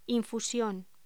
Locución: Infusión
voz